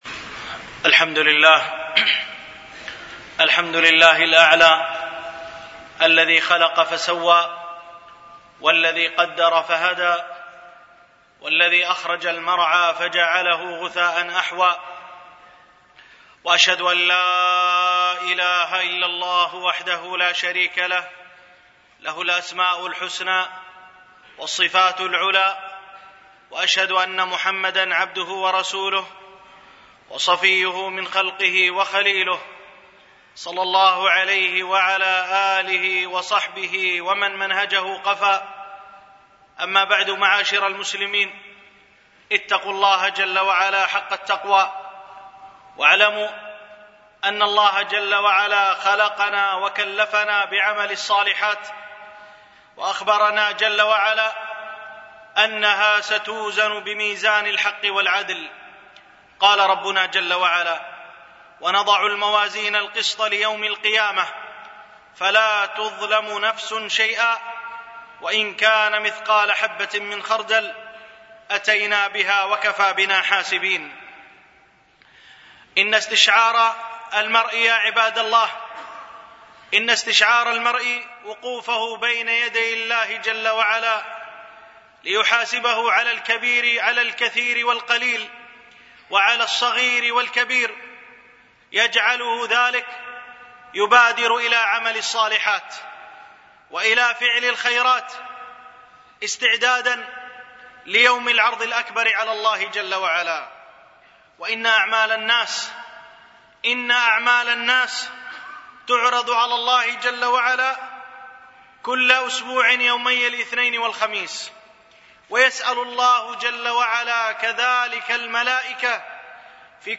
دروس مسجد عائشة (برعاية مركز رياض الصالحين ـ بدبي) المدة